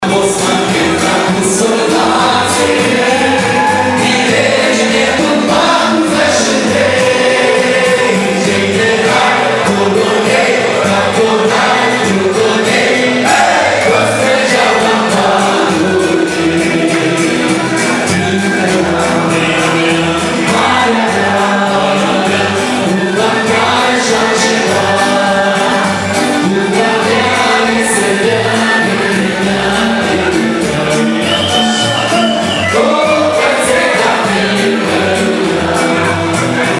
Joi seară, la complexul Ariata Events din Șcheia a avut loc primul concert al ansamblului Plăieșii din Republica Moldova.
Cântecele s-au bucurat de mare succes, fiind aplaudate la scenă deschisă de public.